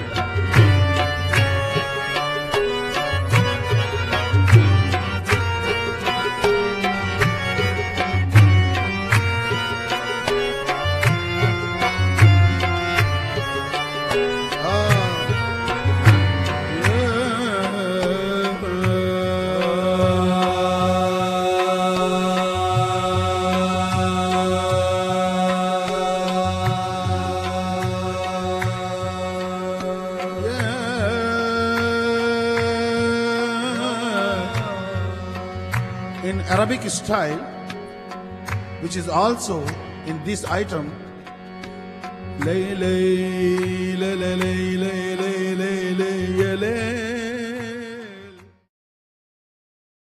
lead vocal, harmonium
support vocal
tabla
dholak
chorus